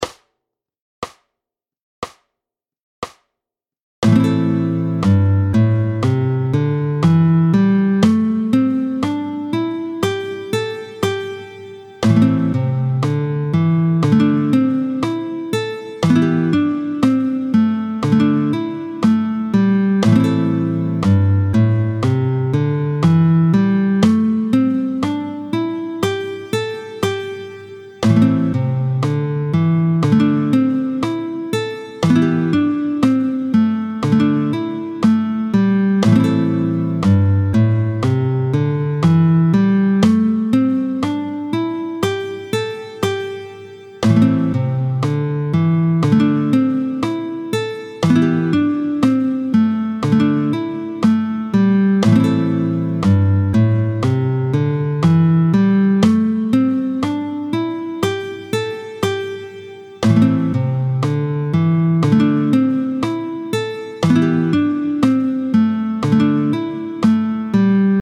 32-03 Lam doigté 5 ; le I IV V mineur, tempo 60
32-03-mineur-doigt-5-I-IV-V.mp3